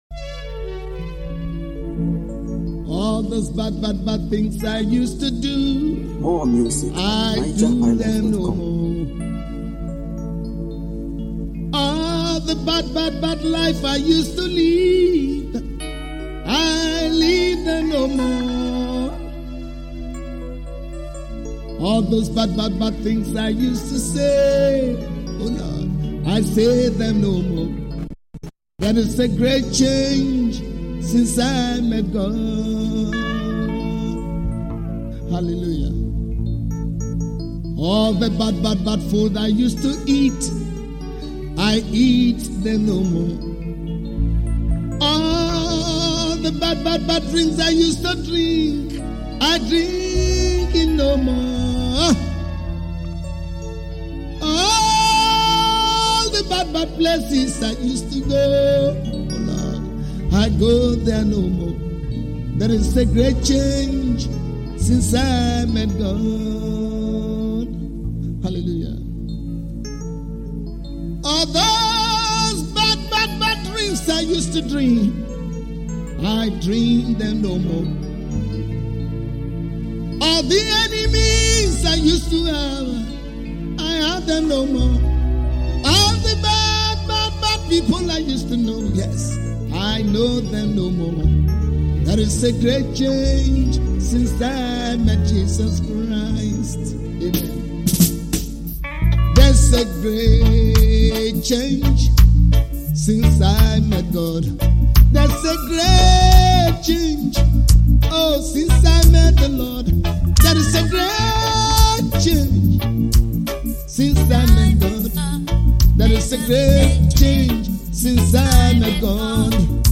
Home » Highlife